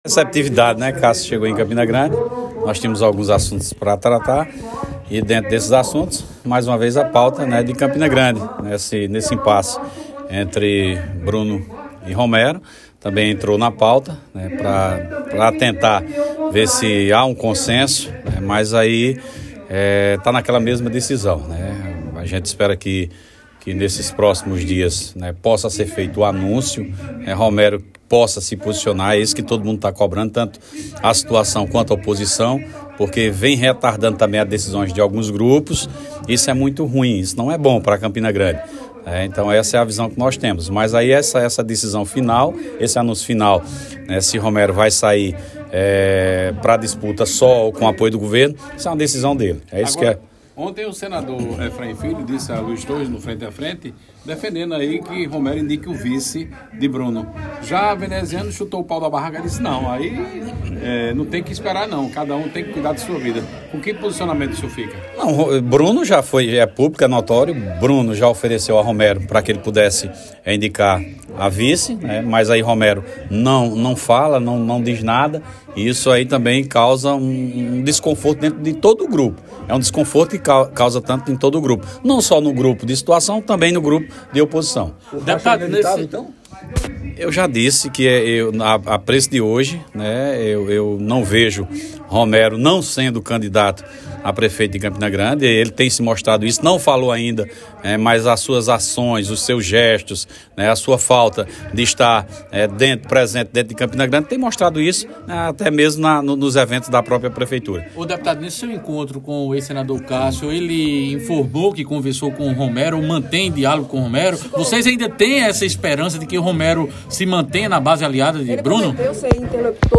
Foi essa a revelação feita pelo deputado estadual Sargento Neto, ao comentar sobre o encontro que teve com o ex-senador Cássio Cunha Lima.